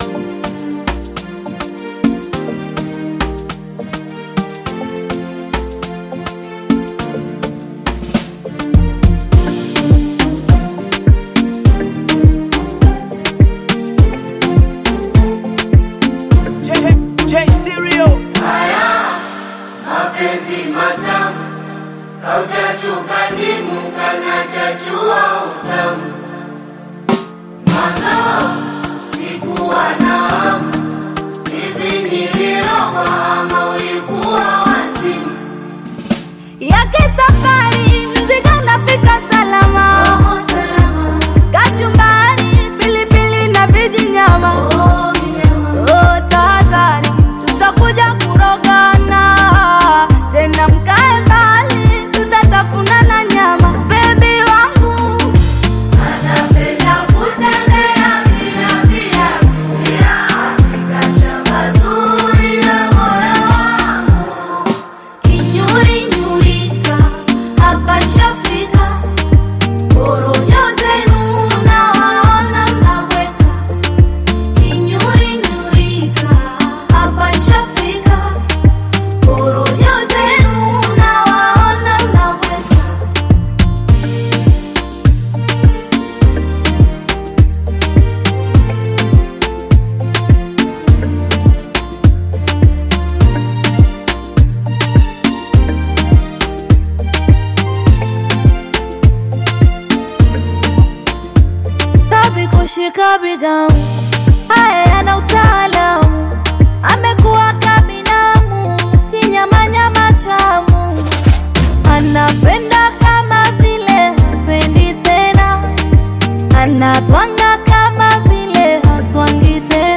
Tanzanian Bongo Flava artists
Bongo Flava